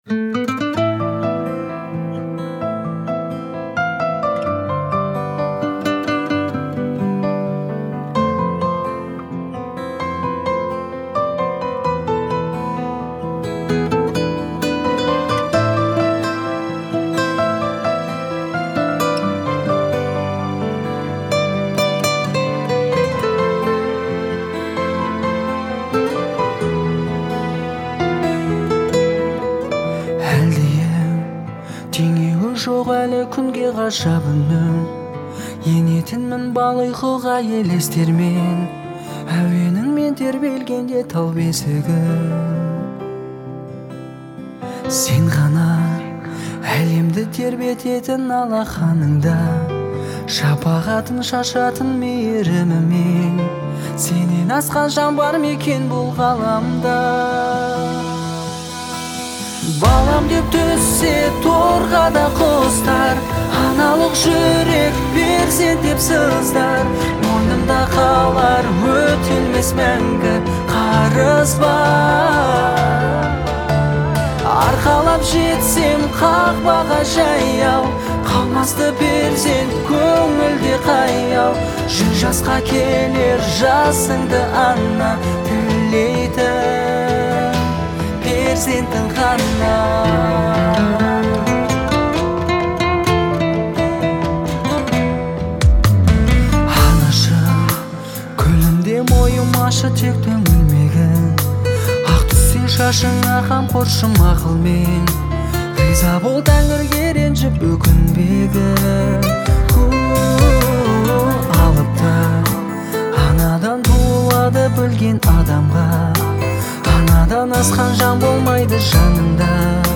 это трогательная композиция в жанре казахской поп-музыки.